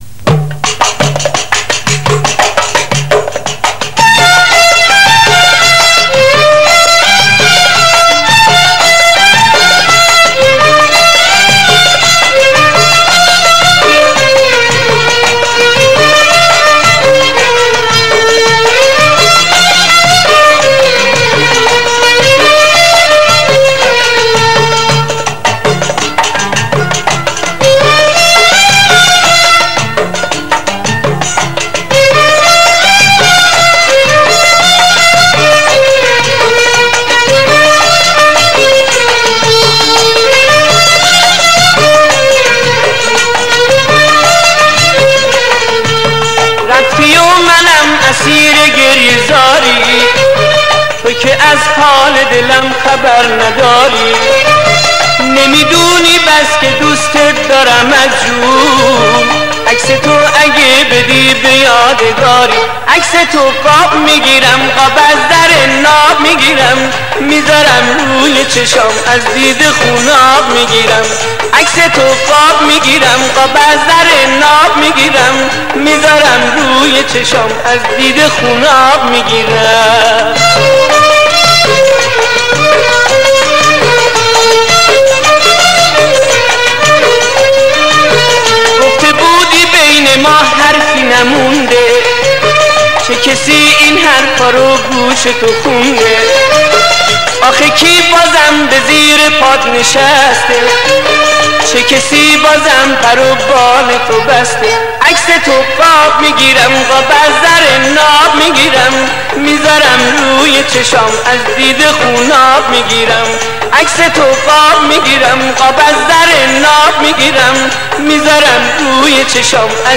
اهنگ شاد